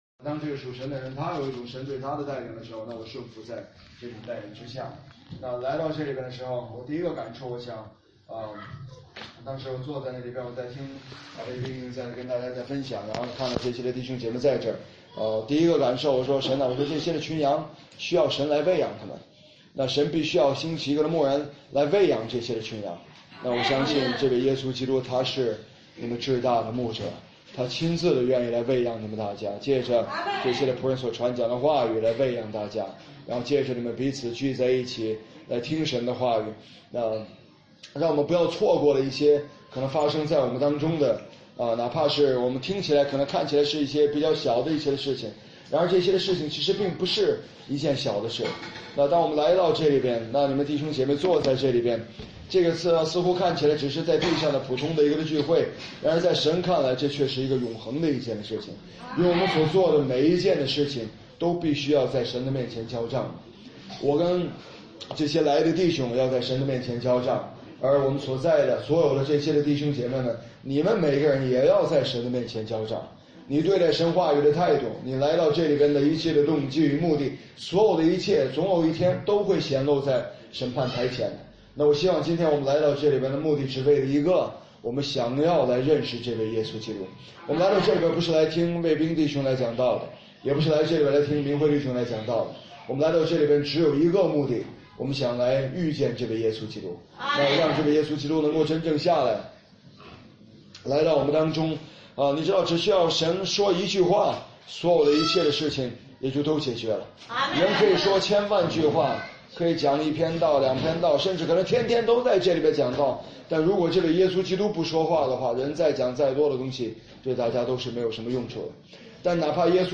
中国讲道录音 – 第 2 页 – 恩典的信息